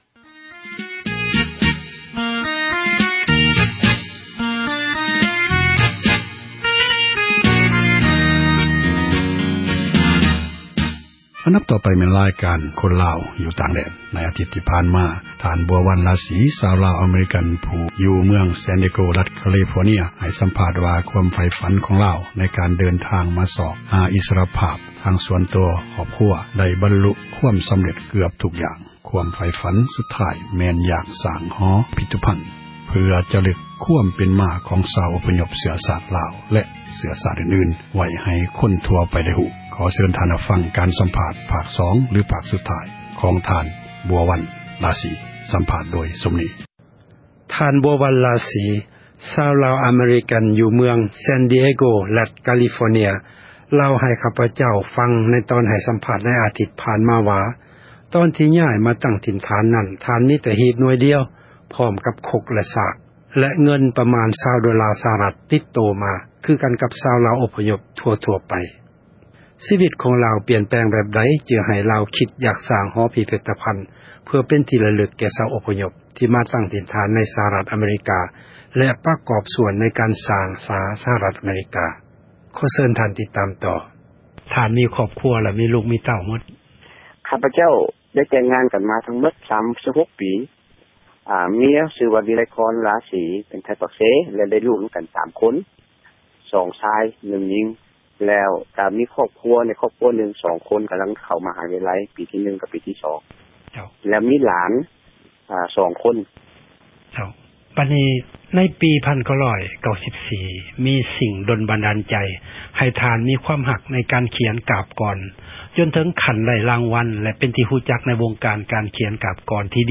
ເຊີນທ່ານ ຮັບຟັງ ການສຳພາດ ພາກ 2 ຫລື ພາກ ສຸດທ້າຍ